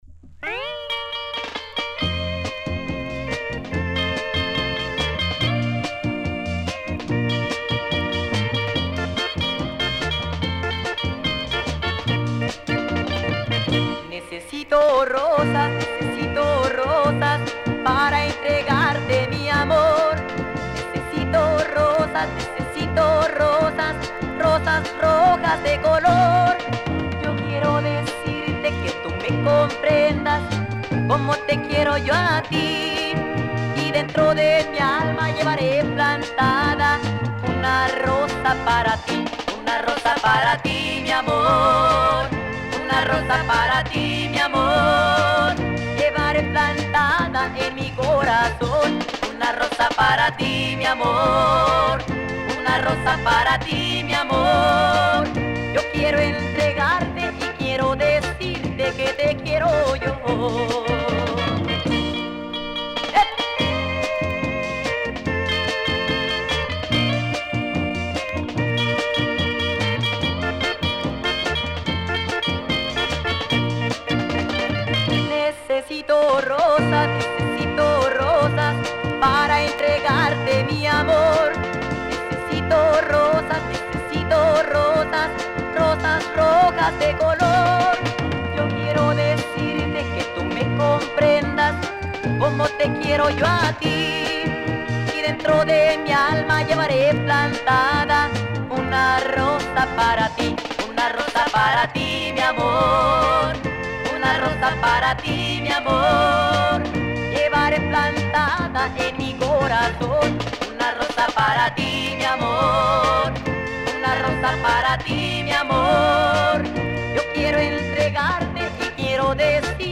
pre-banda romantic love sounds
This song is pretty tame compared to first one.